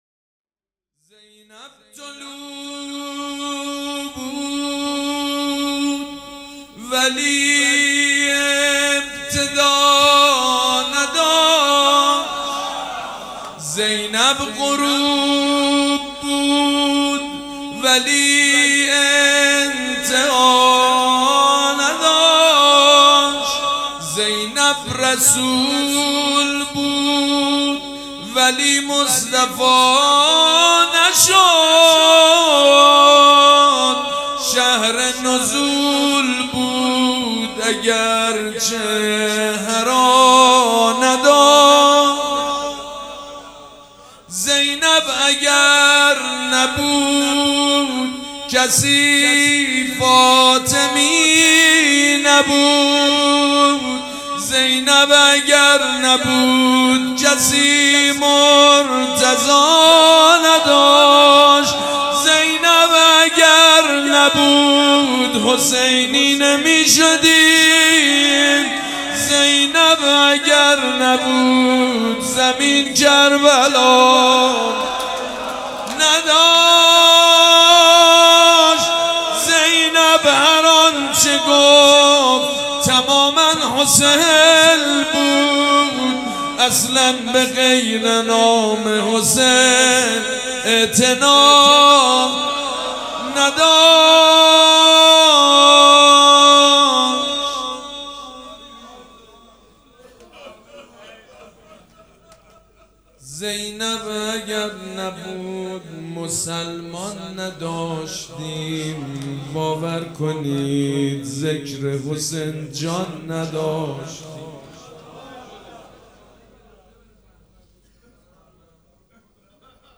روضه بخش دوم
حاج سید مجید بنی فاطمه چهارشنبه 10 مهر 1398 هیئت ریحانه الحسین سلام الله علیها
سبک اثــر روضه مداح حاج سید مجید بنی فاطمه